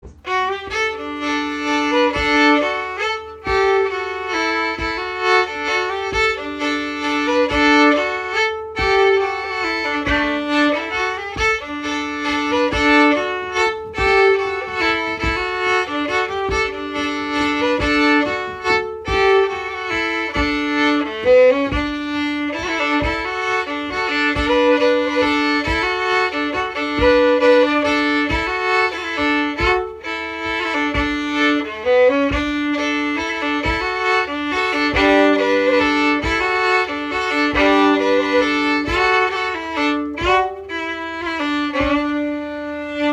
Sessions are open to all instruments and levels, but generally focus on the melody.